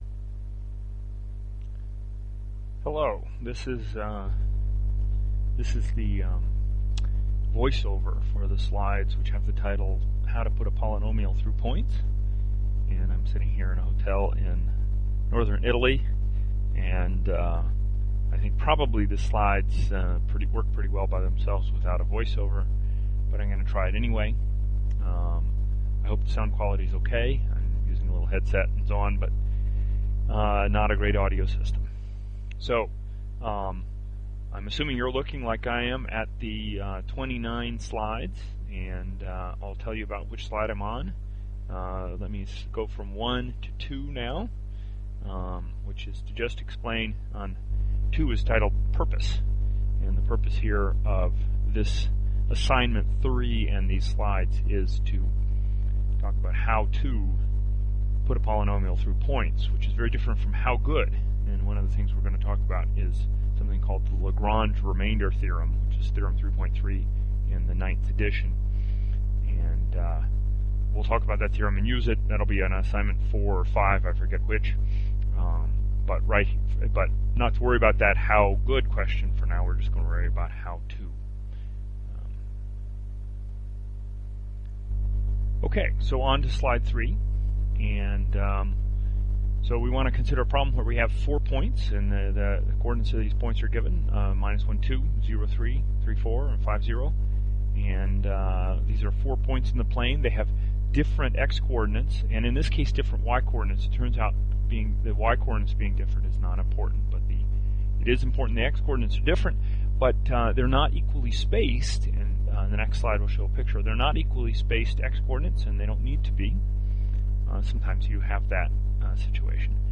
voiceover for slides